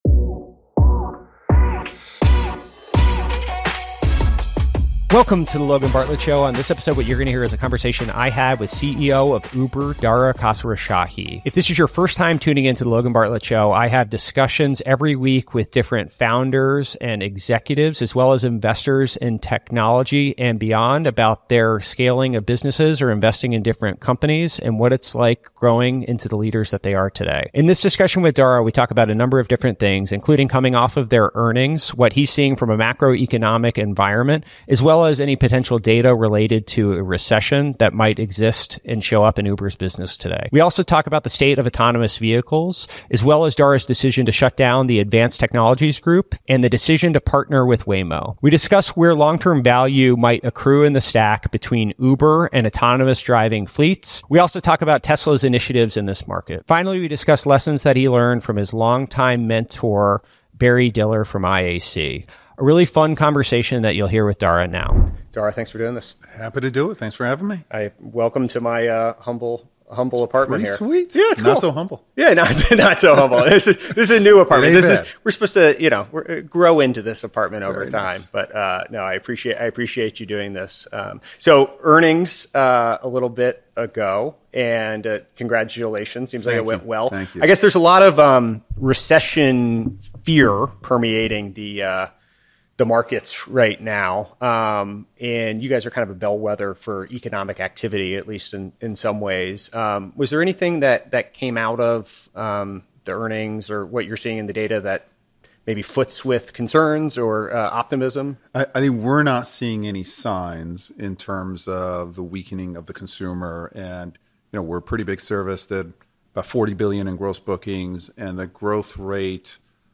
interviews Dara Khosrowshahi